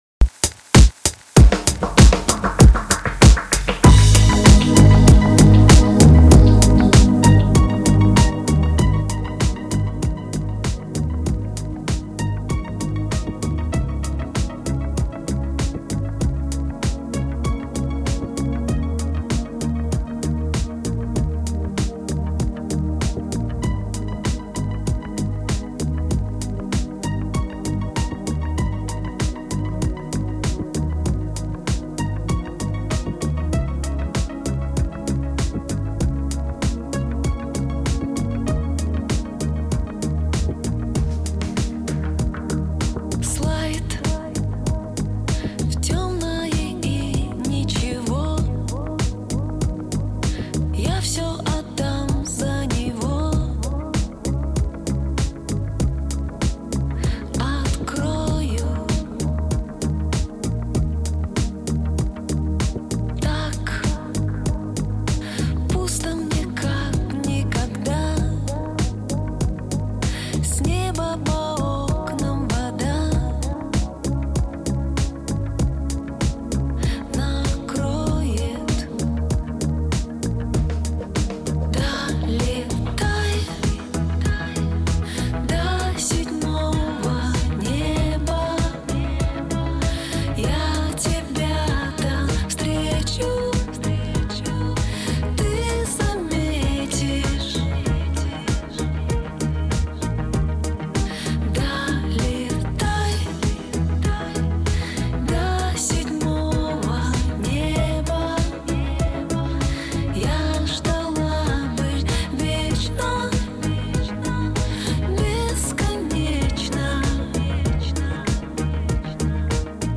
Жанр: Русская эстрада